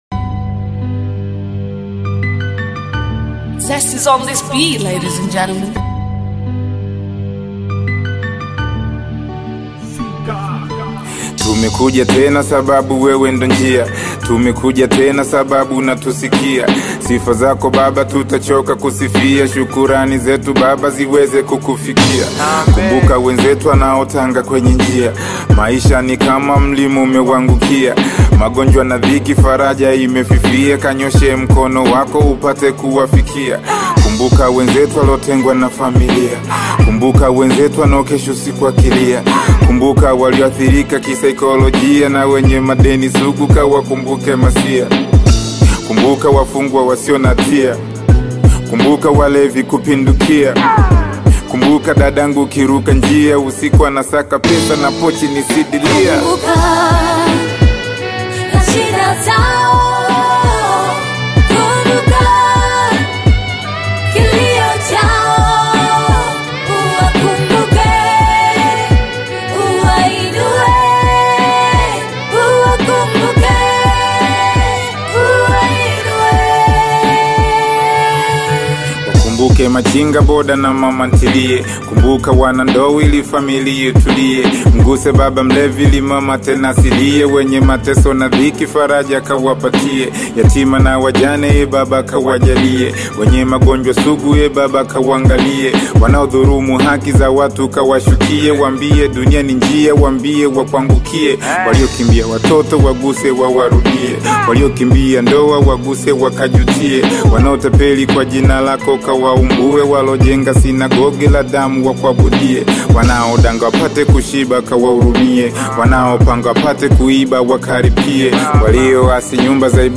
Hip-hop fans
is a high-energy track with a catchy beat and smooth rhymes